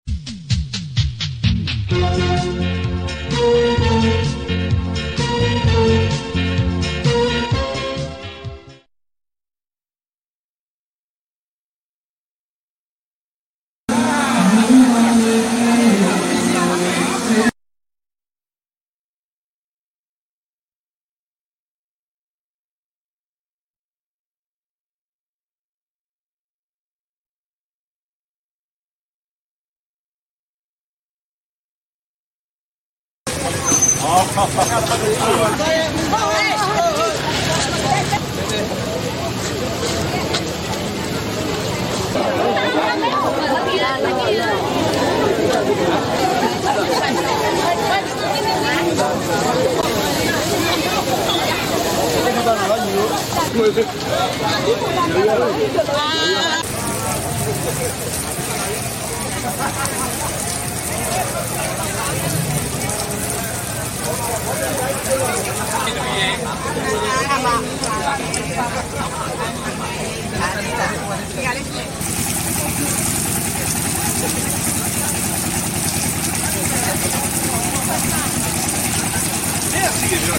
တနင်္သာရီတိုင်းဒေသကြီးဝန်ကြီးချူပ်ဦးမြတ်ကို ပြည်သူများလမ်းလျှောက်သင်္ကြန်အားအေးချမ်းစွာပါဝင်ဆင်နွှဲနေမှုကိုကြည့်ရှုအားပေး ထားဝယ် ဧပြီ ၁၄